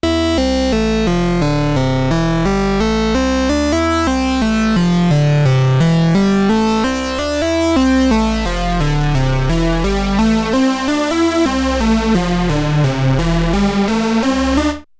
В первом файле играет унисон из всех шести голосов (все оски и сабоски). Сначала всё чётко, потом со второго такта (4 сек) постепенно добавляю расстройку между голосами и сразу появляется эффект фланжера.
Вложения ambika test 1.mp3 ambika test 1.mp3 556,9 KB · Просмотры: 454 ambika test 2.mp3 ambika test 2.mp3 894,1 KB · Просмотры: 429